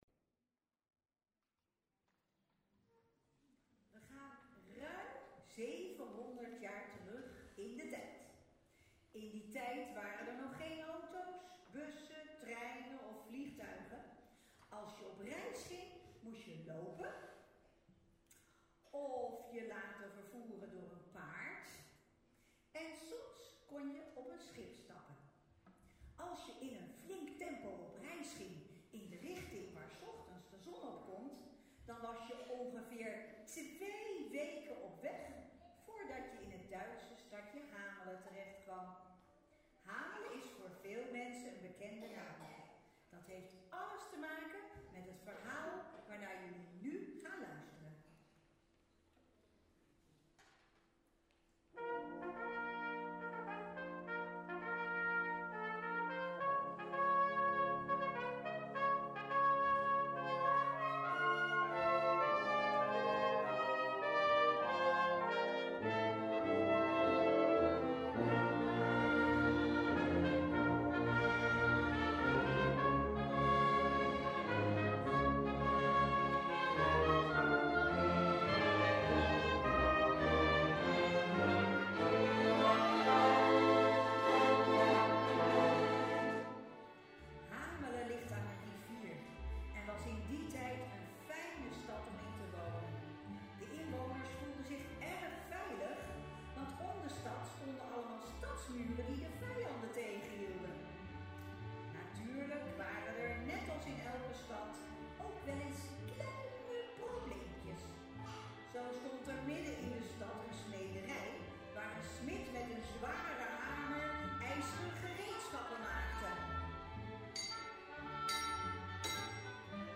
Kinderconcerten
Kinderconcerten Elk jaar geven we met ons orkest een Kinderconcert. Kinderen vanaf 4 jaar, komen kijken en luisteren naar een mooi verhaal met een verteller en muziek.
De concerten worden altijd in Bussum uitgevoerd, vaak in de Wilhelminakerk, de laatste jaren in Spant!, en soms twee keer achter elkaar op een middag.